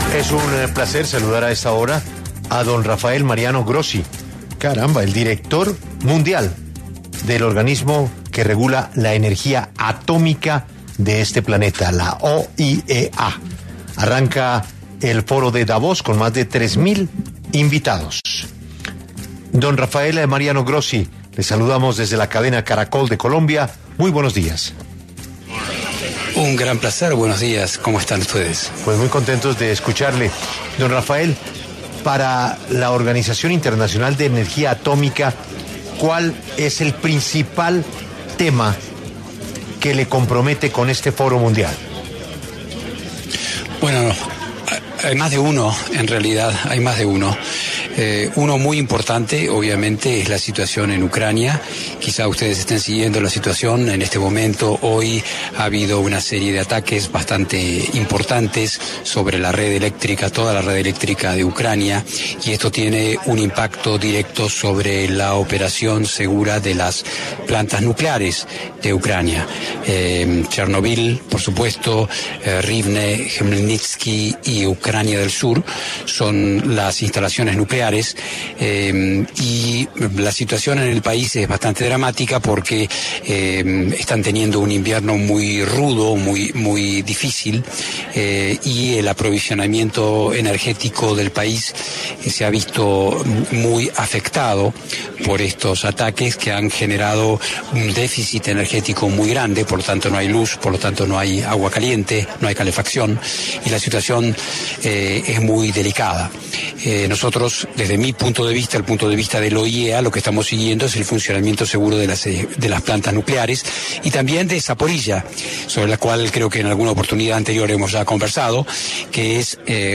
El actual director general del Organismo Internacional de Energía Atómica (OIEA) pidió en los micrófonos de 6AM W el respaldo de Colombia para convertirse en secretario general de las Naciones Unidas.
Rafael Mariano Grossi, director general del Organismo Internacional de Energía Atómica, conversó con 6AM W a propósito del trabajo que hacen para el control de plantas y armas nucleares en todo el mundo, además de solicitar el apoyo de Colombia en la votación para ser nuevo secretario general de las Naciones Unidas.